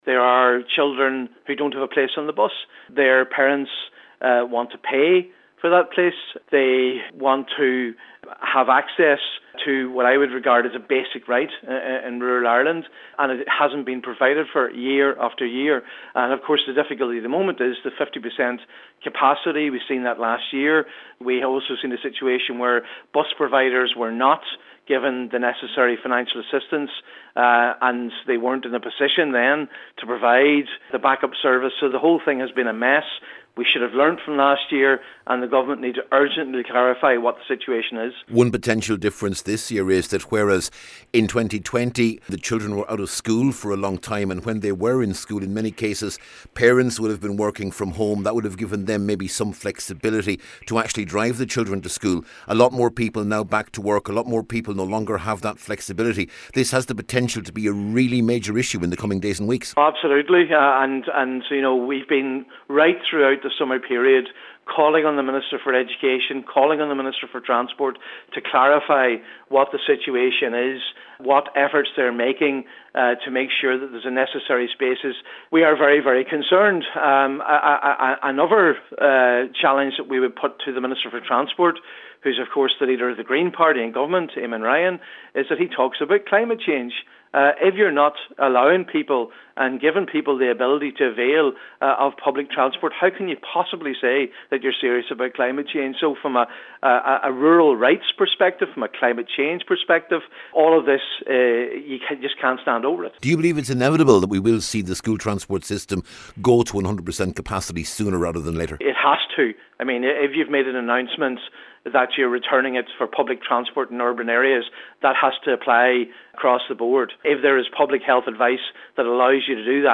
Donegal Deputy Padraig MacLochlainn says this has been an issue in Donegal for years, and clarity is needed now……..